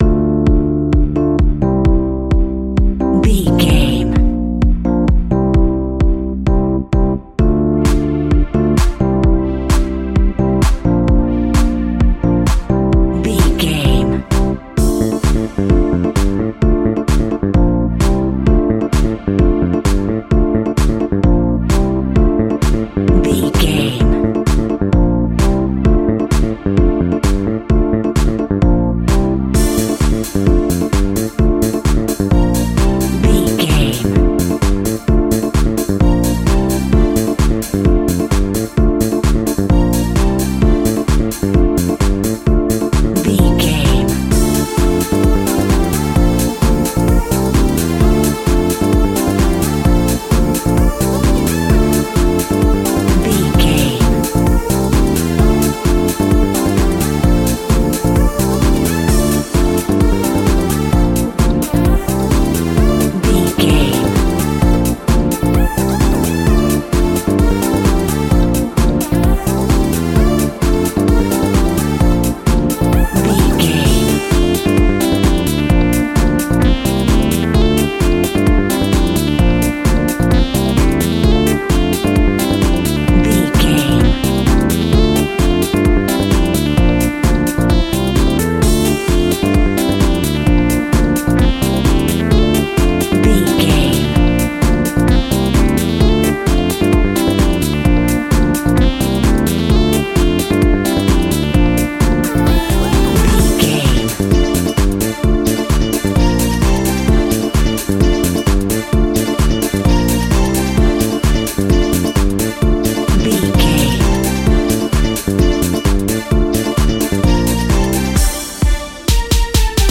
Retro Old Skool Funky House.
Aeolian/Minor
D
groovy
energetic
uplifting
hypnotic
drum machine
electric piano
synthesiser
electric guitar
funky house
upbeat
wah clavinet
synth bass